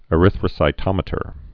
(ĭ-rĭthrə-sī-tŏmĭ-tər)